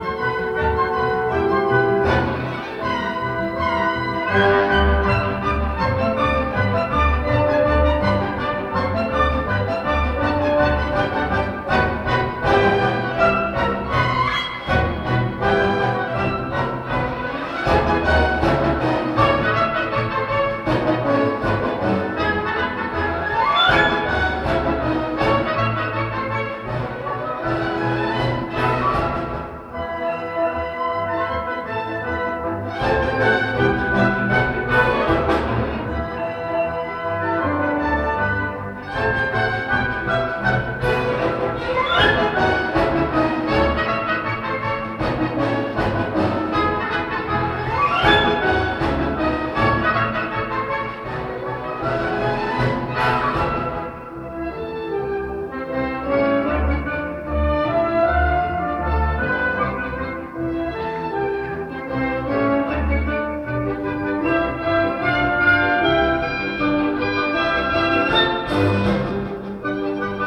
Угодайко - что за сабж звучит в этой ужасной моно-записи с телефона? upd: вложение теперь латиницей.